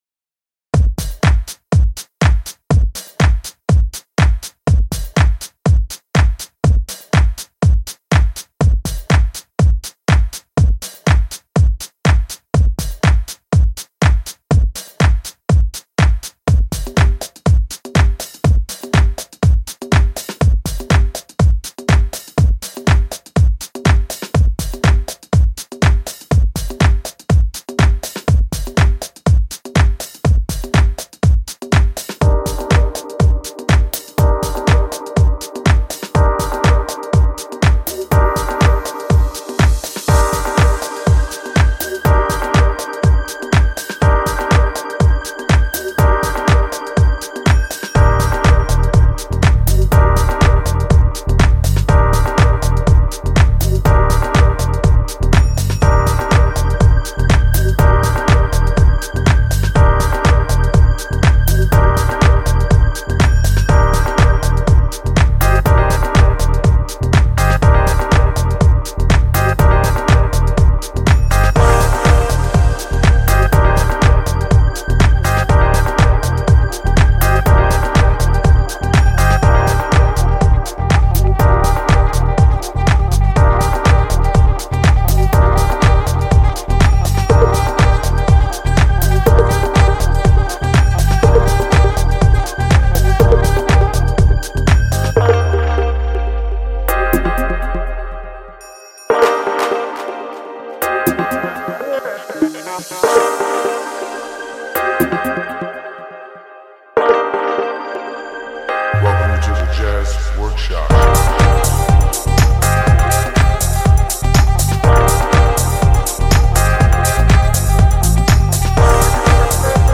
a jazzy oriented house track for wrapping the release.